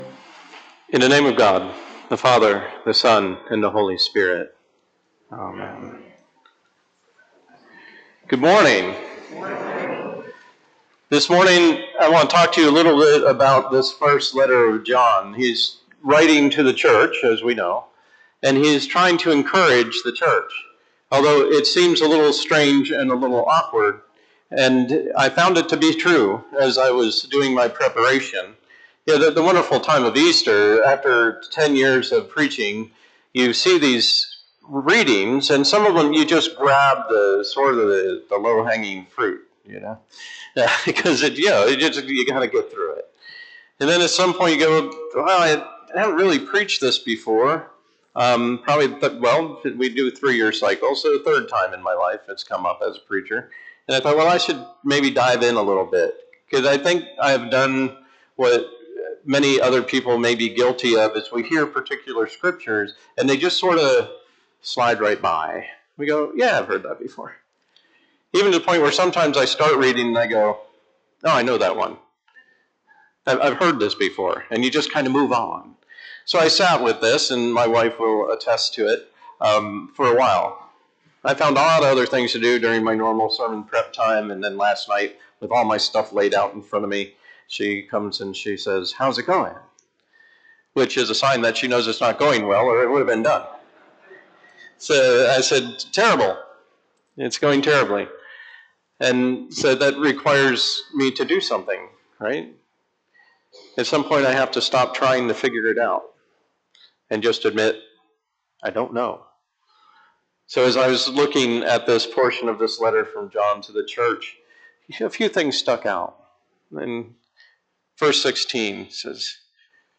Sermons
Click on the date that you missed below to replay the sermon audio from our past services.